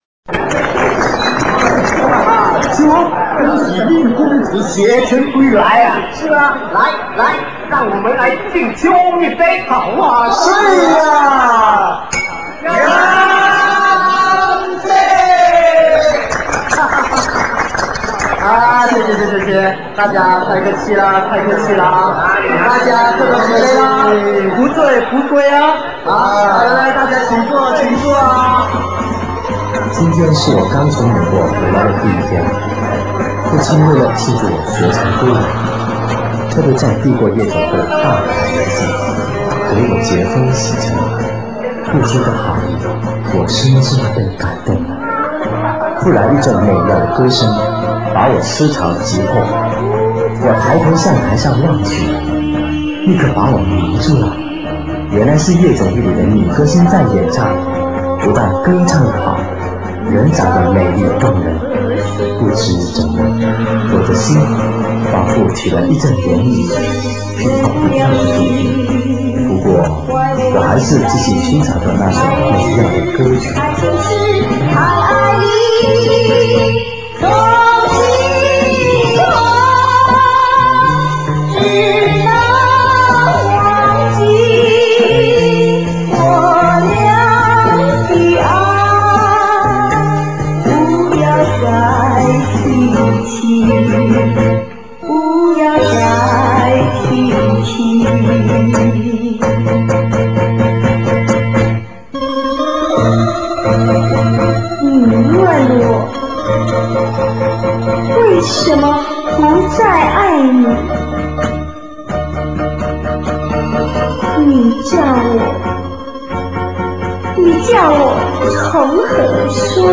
一对情歌对唱的老歌星